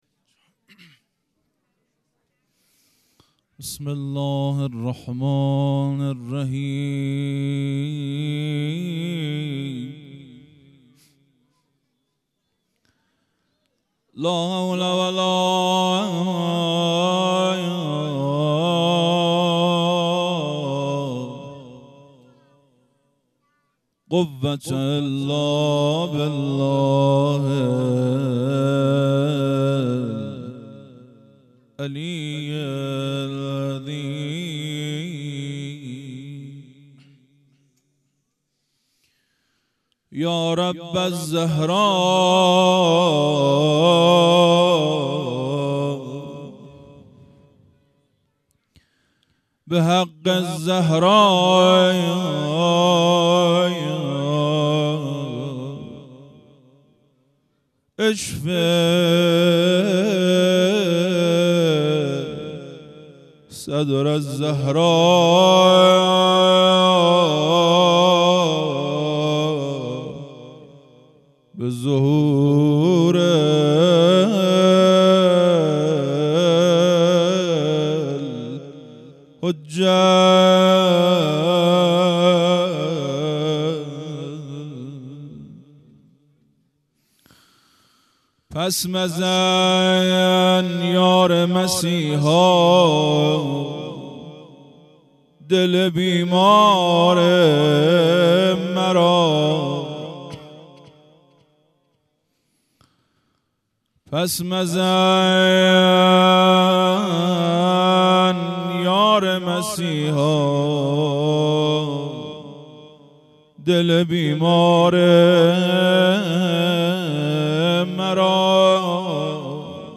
هیئت مکتب الزهرا(س)دارالعباده یزد
0 0 روضه